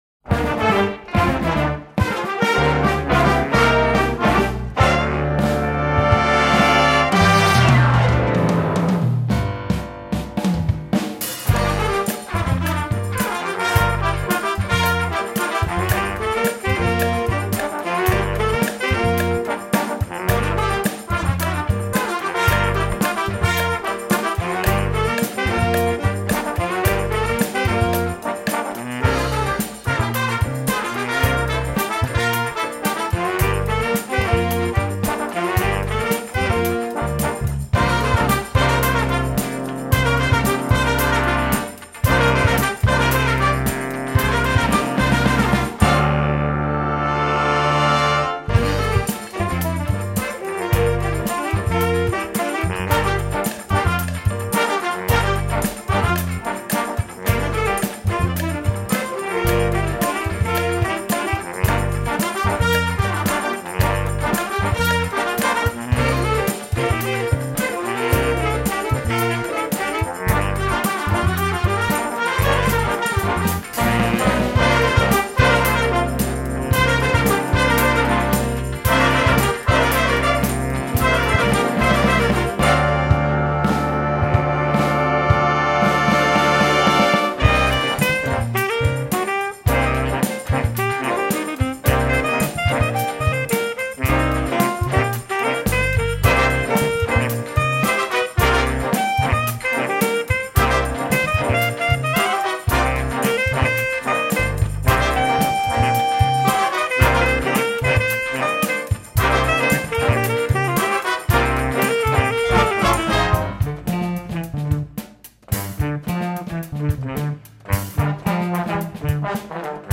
Den Instrumentalisten stehen drei verschiedene AGs unterschiedlicher Stilrichtungen zur Verfügung: Es gibt ein Streicher-Ensemble, das große sinfonischen Blasorchester und die vollbesetzte groovige Big Band.
Hörbeispiel 04 der JVG Big Band: